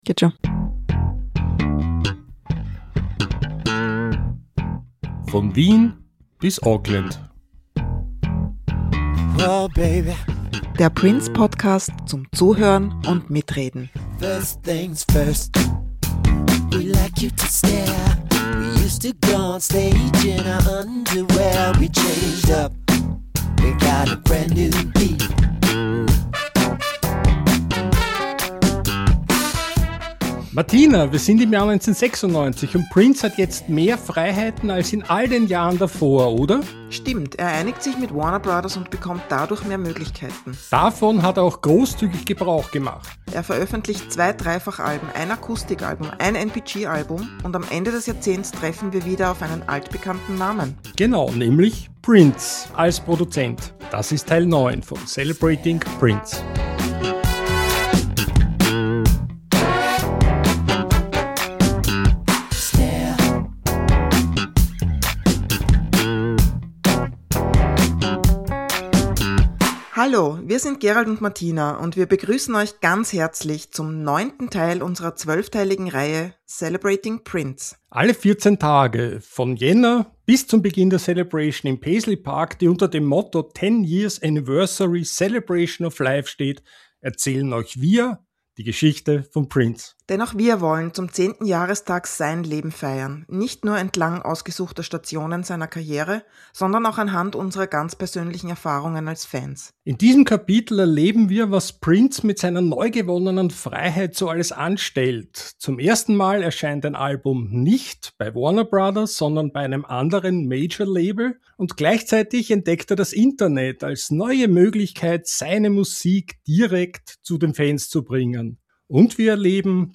Zwei Stimmen.